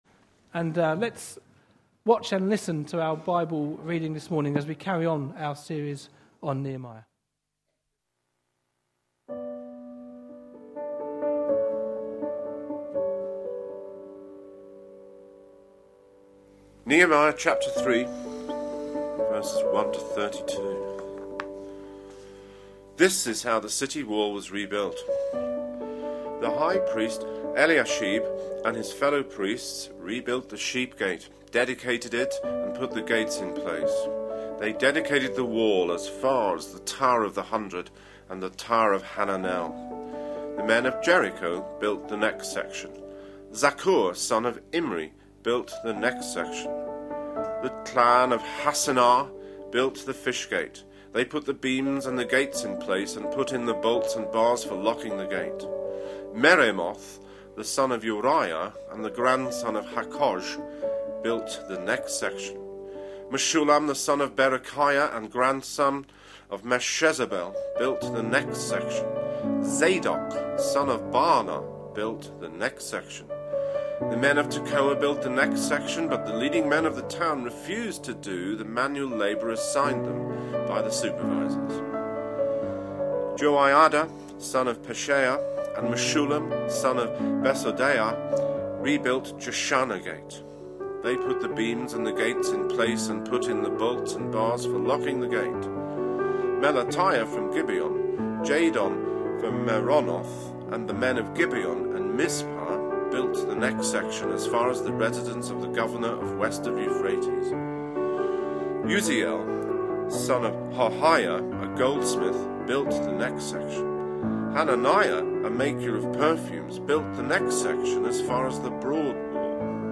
A sermon preached on 29th June, 2014, as part of our Another Brick In The Wall. series.